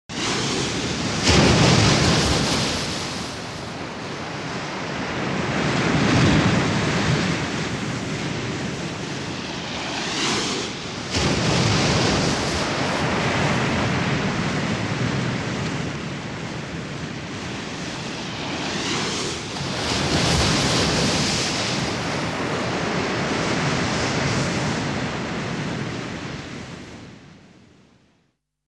SFX声势浩大的海浪拍击音效下载
SFX音效